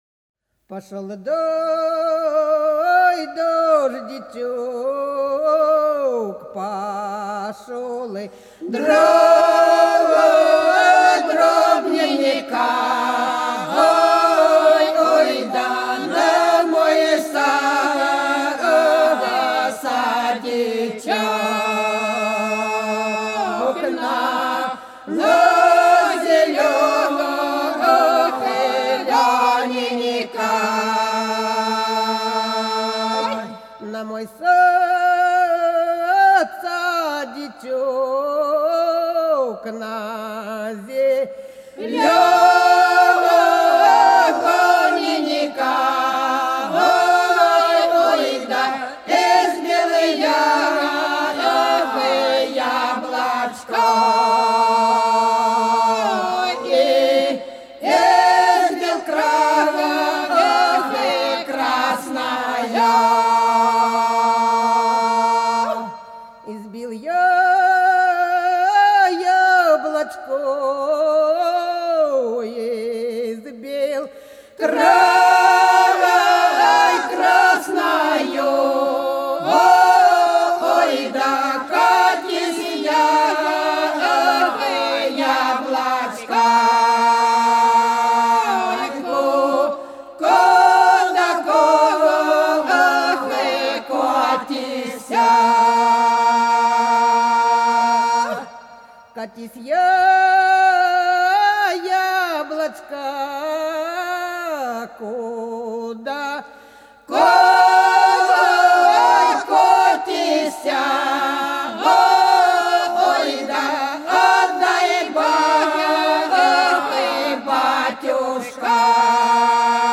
Хороша наша деревня Пошел дождичек - протяжная (с. Репенка)
08_Пошёл_дождичек_-_протяжная.mp3